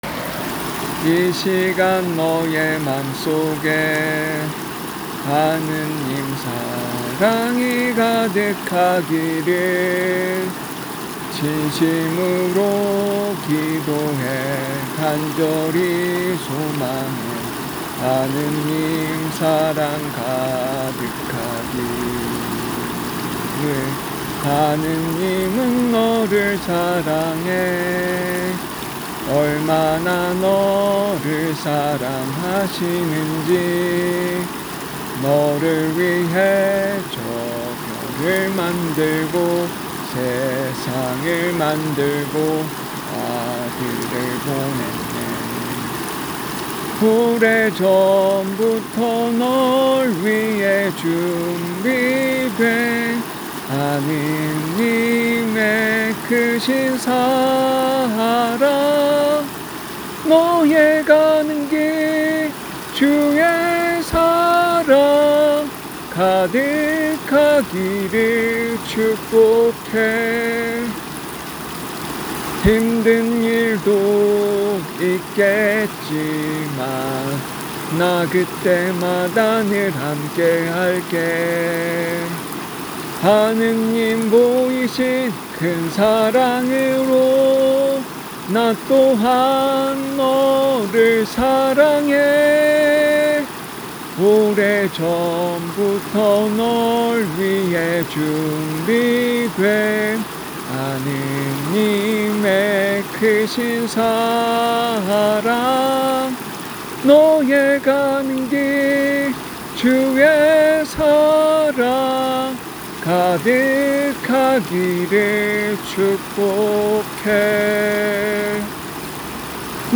그래서 노래는 미리 휴대폰에 녹음을 하고, 그걸 틀어 놓고 수화로 내 마음을 전하기로 정했다.